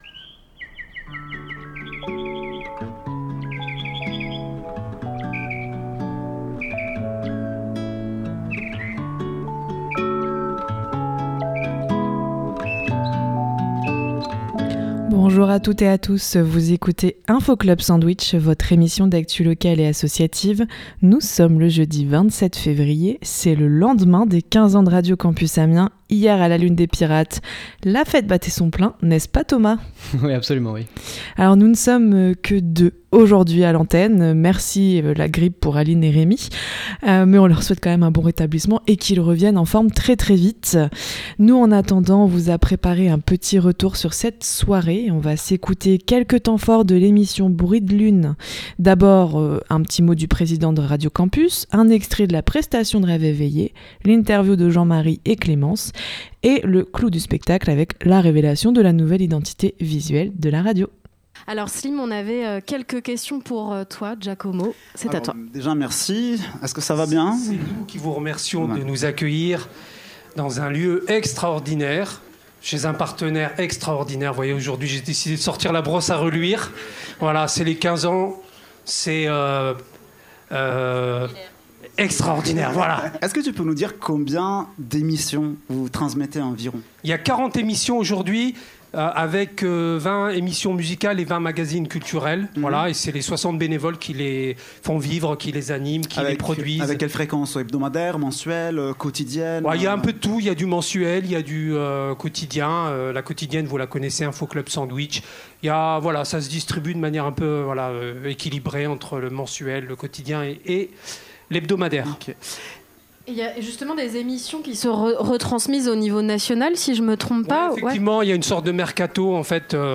Aujourd'hui, on vous propose un retour sur les 15 ans avec plusieurs extraits de l'émission Bruit de Lune d'hier !